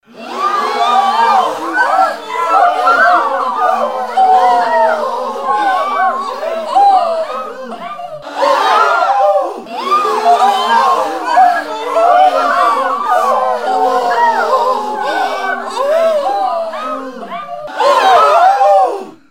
AMBIANCES SONORES DE FOULES
Panique 4 (foule-cris)